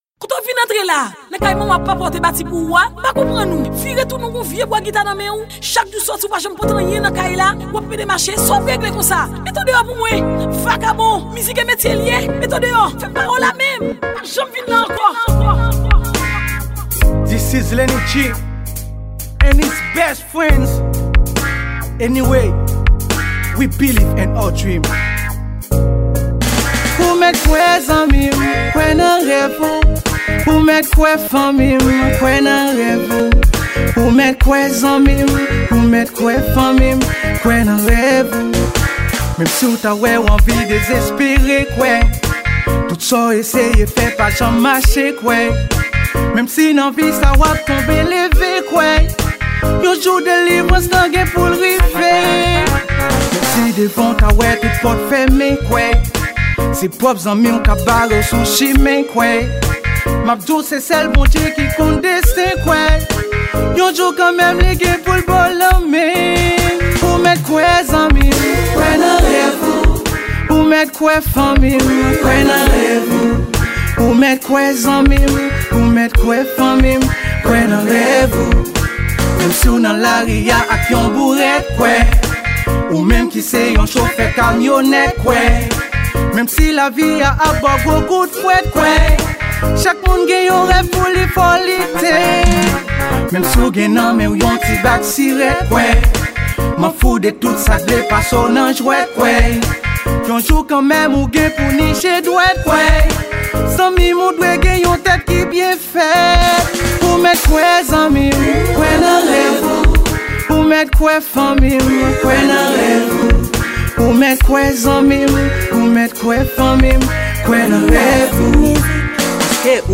Genre: Raggae.